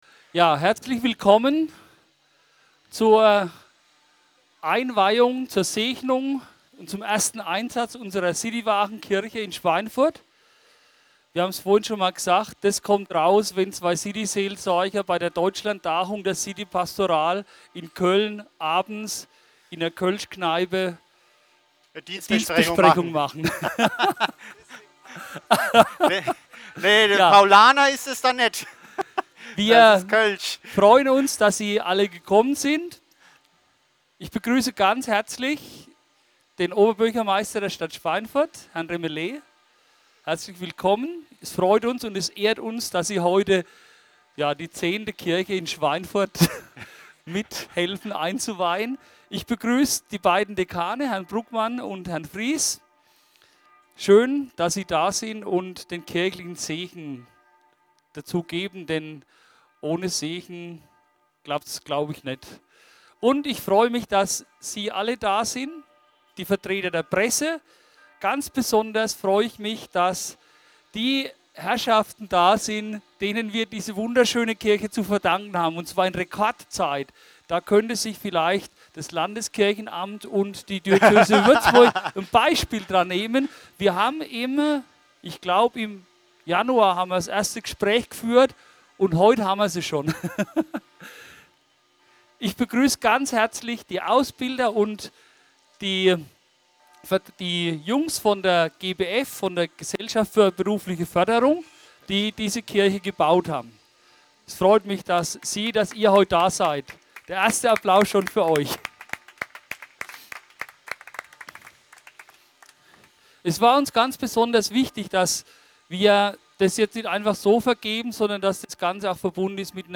Einweihung der Wagenkirche: Begrüßung